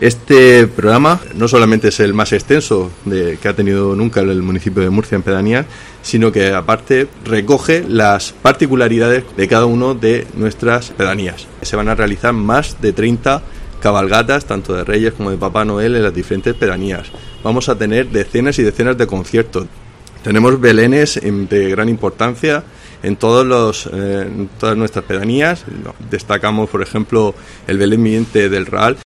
Marco Antonio Fernández, concejal de pedanías